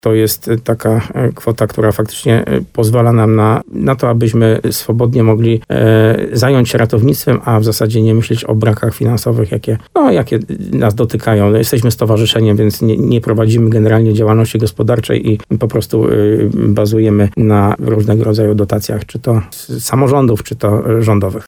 Rozmowa